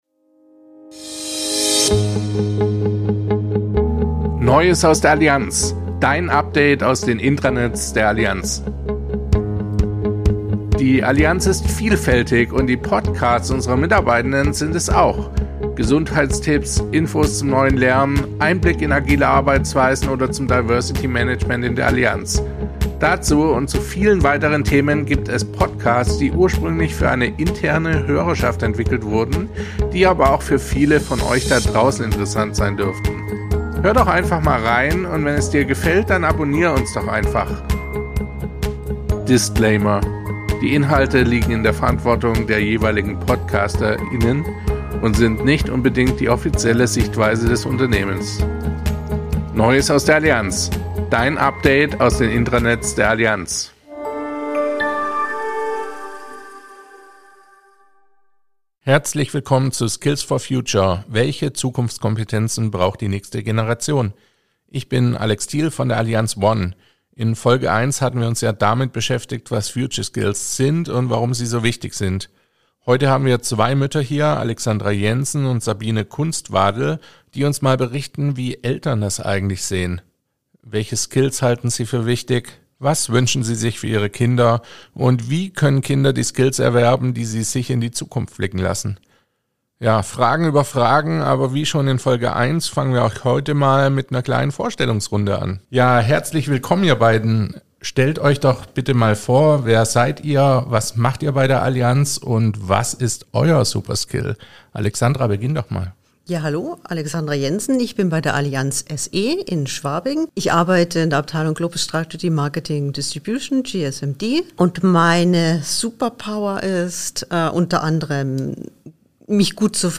Beschreibung vor 1 Jahr Welche Skills wünschen sich Eltern für ihre Kinder – und wie stellen sie sicher, dass diese erworben werden? Was kann und soll unser Bildungssystem leisten? Zwei Allianz-Kolleginnen diskutieren, wie das Familienleben mit Schulkindern & Karriere aussehen kann – und welche Rolle für sie die Persönlichkeitsentwicklung ihrer Kinder hat.